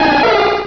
pokeemmo / sound / direct_sound_samples / cries / aipom.wav